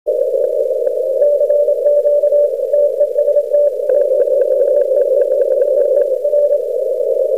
コンテスト中に録音したオーディオファイルを少し整理してみました。
またリグのLine-out端子にはサイドトーンが出てこないようで、小生が送信してる部分は無音状態になってしまってます。
同上なのですが、DPでも聞こえるぐらい強いですね。